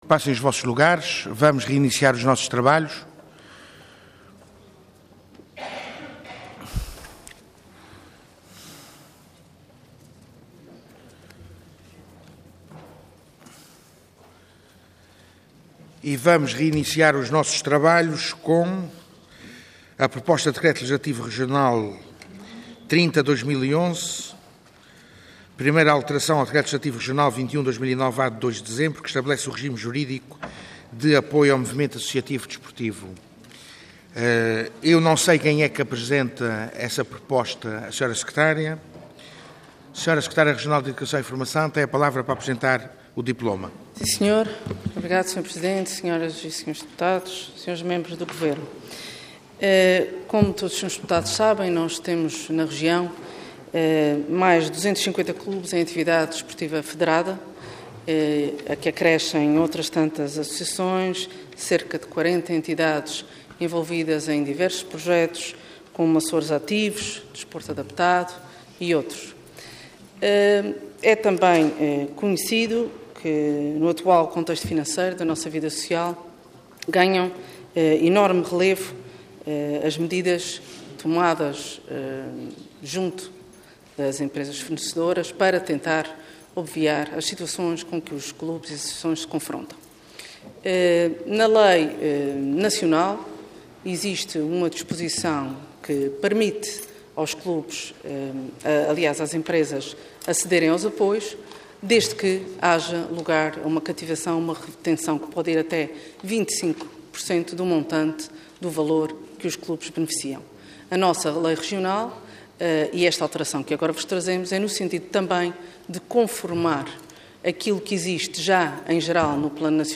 Assembleia Legislativa da Região Autónoma dos Açores
Intervenção
Secretária Regional da Educação e Formação